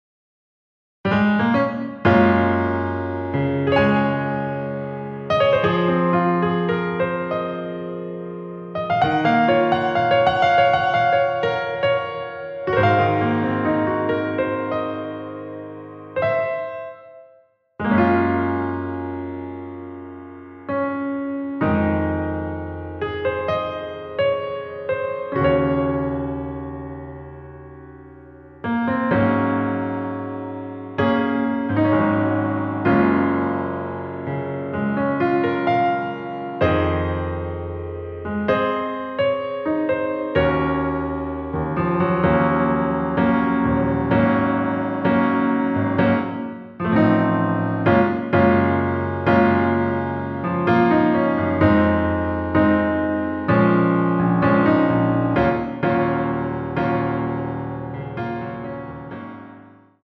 Db
◈ 곡명 옆 (-1)은 반음 내림, (+1)은 반음 올림 입니다.
앞부분30초, 뒷부분30초씩 편집해서 올려 드리고 있습니다.
중간에 음이 끈어지고 다시 나오는 이유는